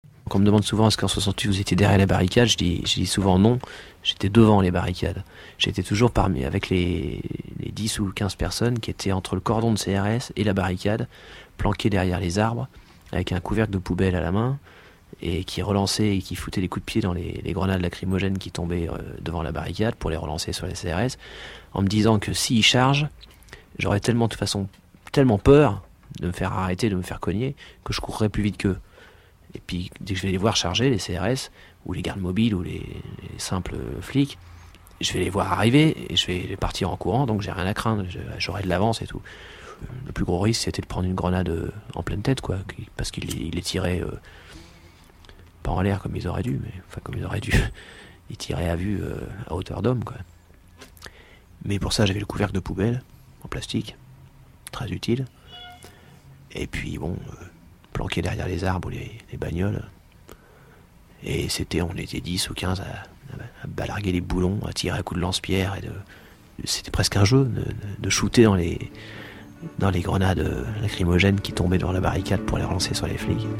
• Interview de Renaud à France Culture